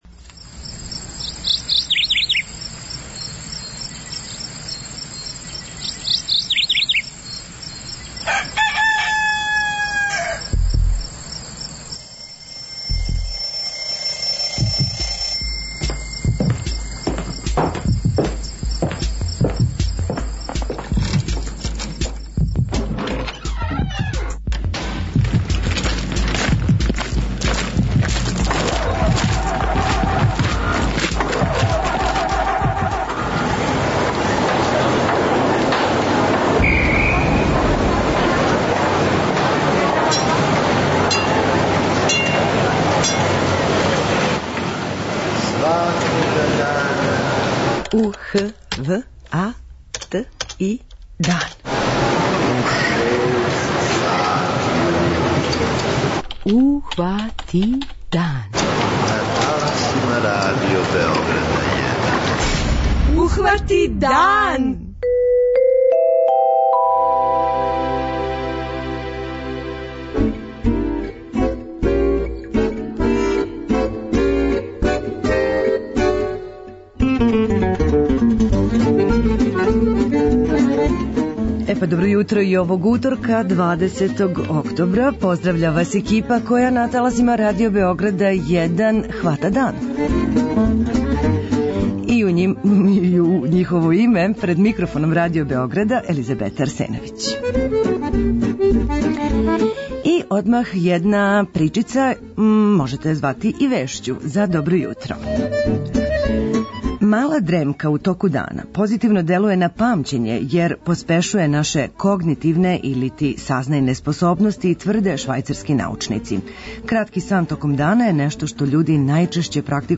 - гост Јутарњег програма биће глумац Зијах Соколовић, који ће најавити премијеру представе "Демократија", за коју је написао текст, режирао је и глуми у њој заједно са Драженом Шиваком; сутра увече, најпре у Новом Саду, а потом у још девет градова Србије, представа ће се играти у оквиру кампање „Бирам кога бирам", који спроводе Национална коалиција за децентрализацију, организација "Србија у покрету" и Медиа и реформ центар Ниш, са циљем да грађанима Србије покажу све мањкавости постојећег изборног система како би их ангажовали да се активно укључе у кампању за његову промену;
- 20. октобар је Дан ослобођења Београда у Другом светском рату, што ће и ове године бити обележено одавањем поште ослободиоцима, а истог датума град Врбас обележава свој дан, па ће наш гост телефоном бити потпредседник општине, Милан Глушац;